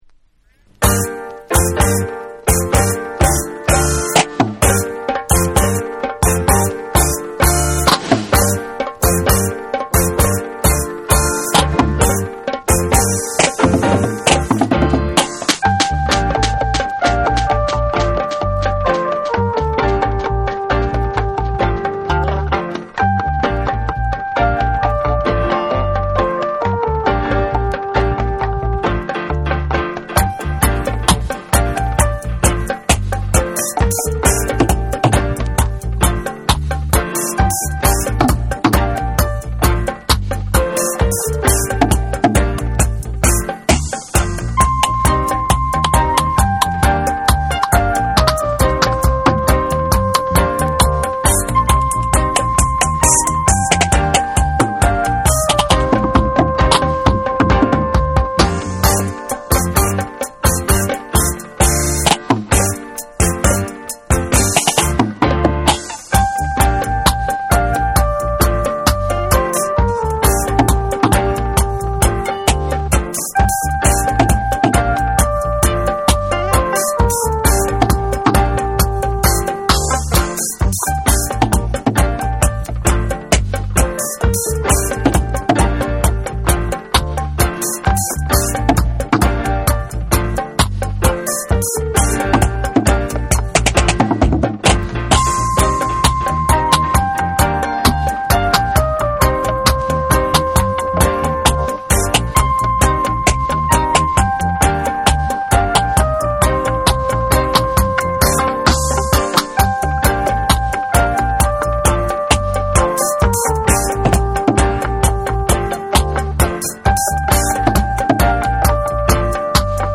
ナイヤビンギ調のパーカッションが絡む躍動するリズムに、鍵盤のメロディーが耳に焼きつくキラー・ステッパー！
REGGAE & DUB